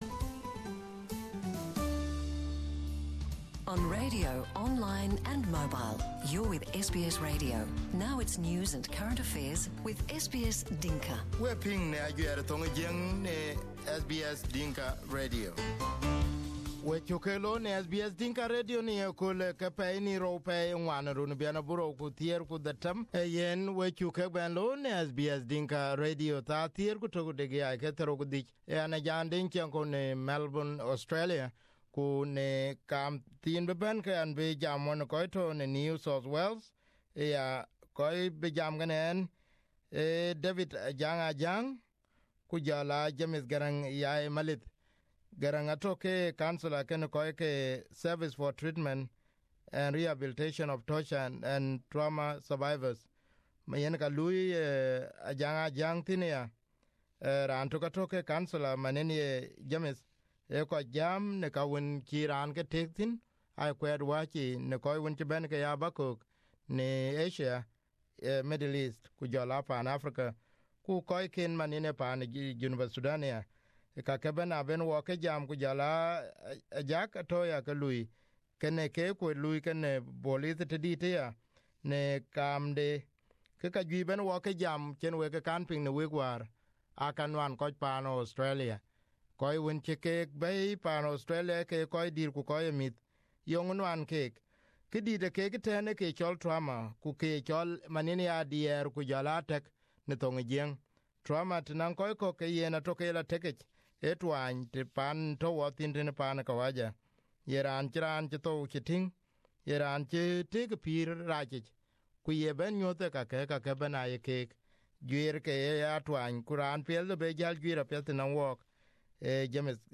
This is an in depth discussion on issues facing South Sudanese in Australia and the role played by Startts.